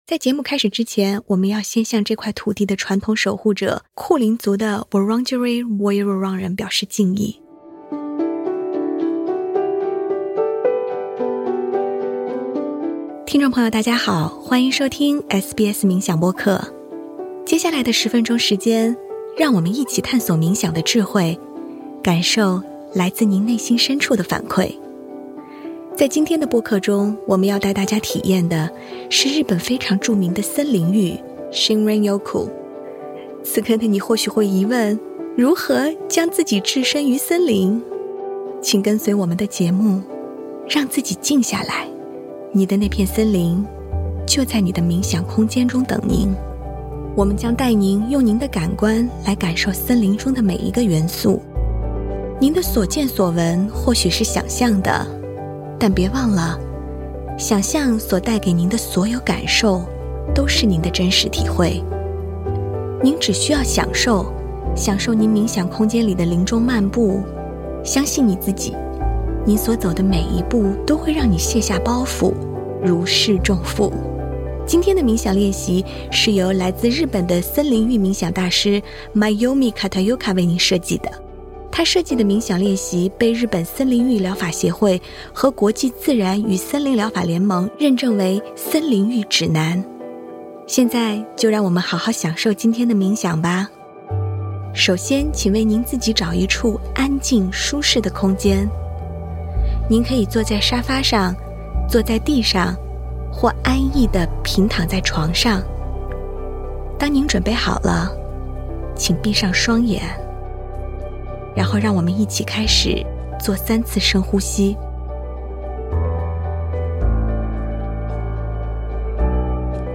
Shinrin-yoku在日文中是“森林浴”的意思。本期播客，您将在您的冥想空间中体验丛林漫步的舒缓与放松。